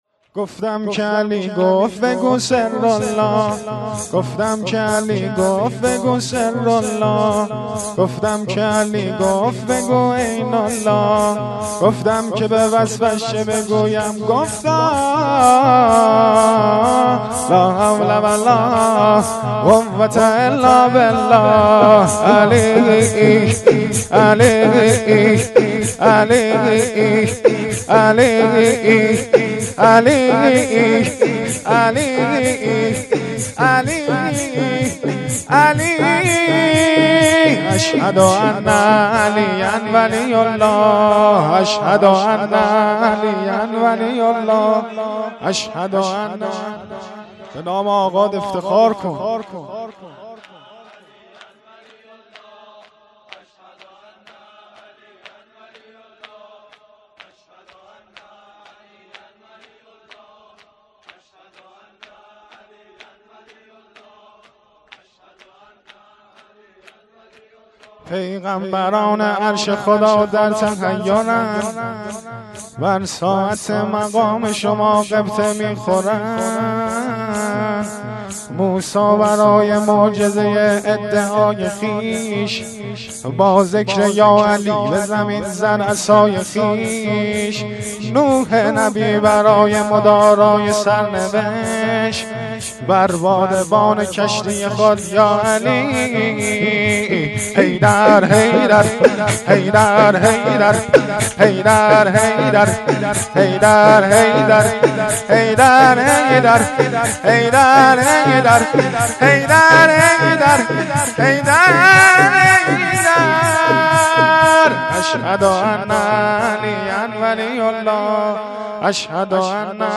شهادت حضرت ام البنین سلام الله علیها- آذرماه ۱۴۰۳